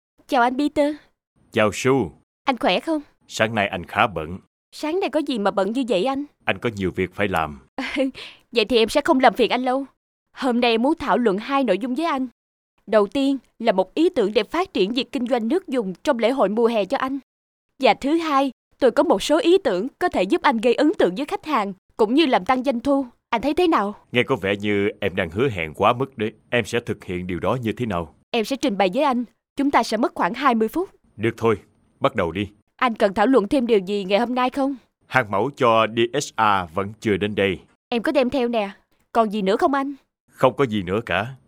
I am a professional voice artist with a good voice and 4 year experience in voiceover and dubbing.
Kein Dialekt
Sprechprobe: Sonstiges (Muttersprache):